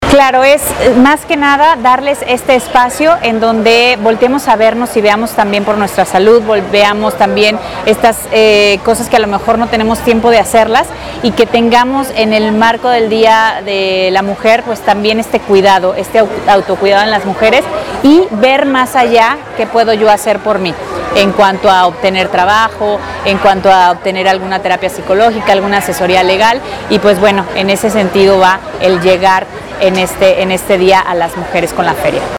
AudioBoletines
Georgina Aboytes Guerrero, directora del INMIRA